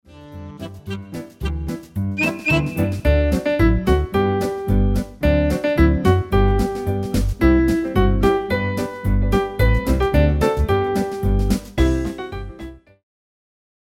RÉPERTOIRE  ENFANTS
Le PLAY-BACK mp3 est la version
instrumentale complète, non chantée,